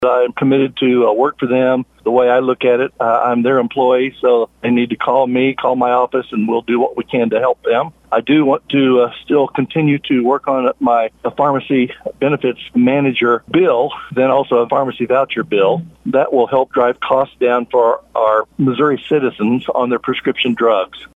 Wright explains he's looking forward to getting back to work for the people.